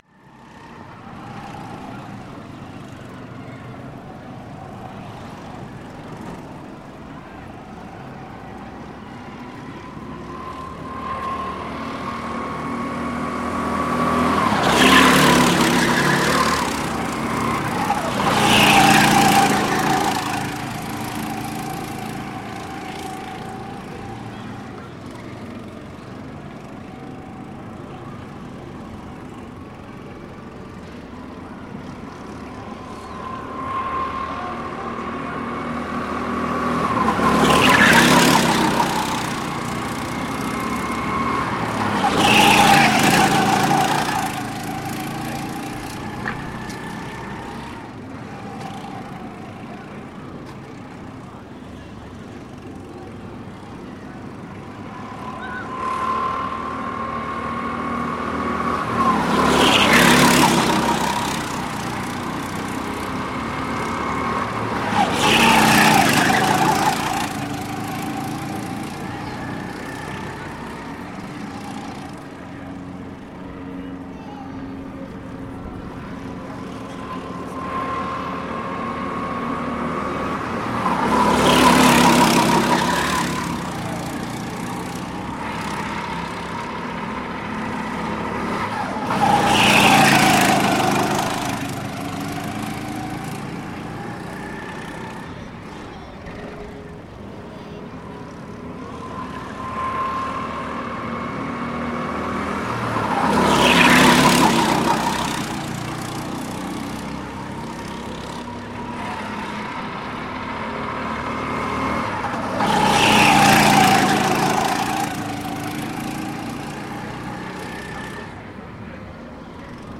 два гонщика соревнуются на картодроме